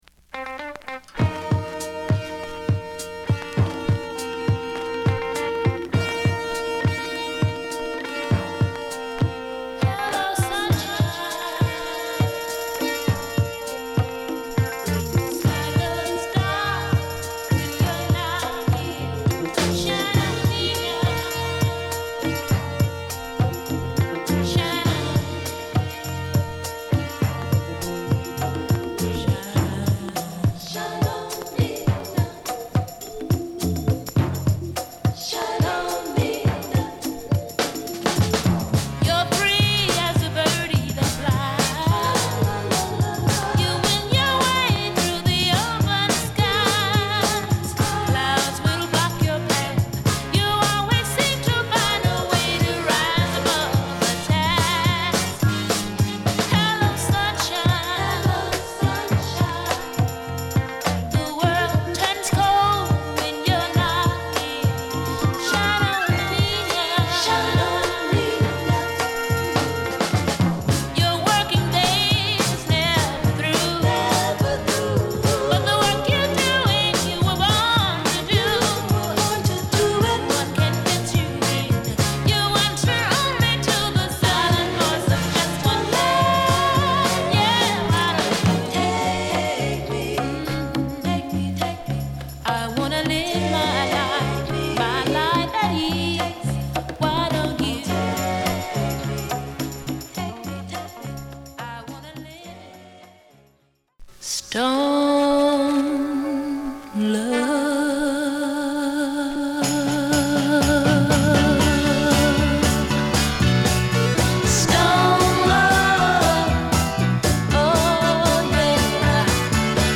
パーカッションやタンバリン等、賑やかなリズム隊をバックにした同タイプのガールズ／ノーザンチューン